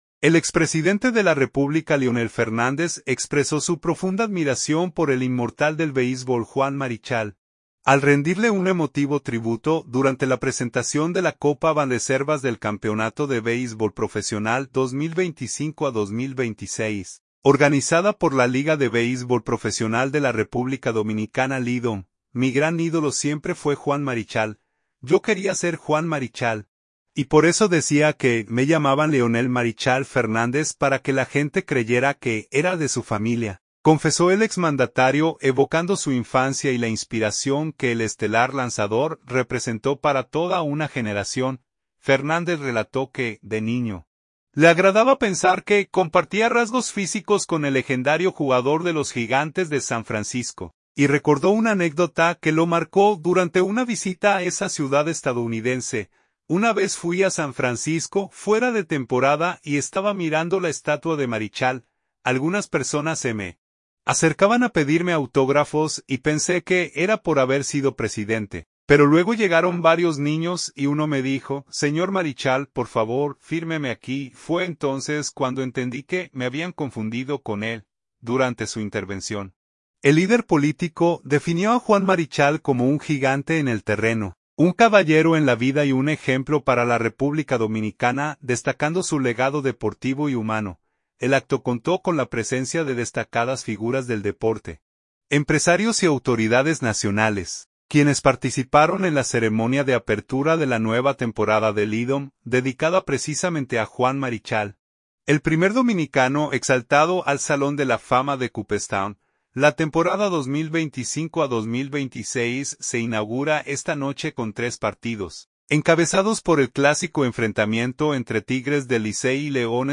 Santo Domingo.- El expresidente de la República Leonel Fernández expresó su profunda admiración por el inmortal del béisbol Juan Marichal, al rendirle un emotivo tributo durante la presentación de la Copa Banreservas del Campeonato de Béisbol Profesional 2025-2026, organizada por la Liga de Béisbol Profesional de la República Dominicana (LIDOM).